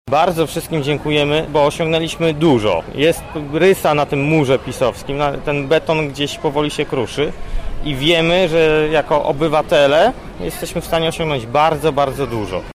briefing kod